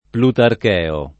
plutarcheo [ plutark $ o ] agg.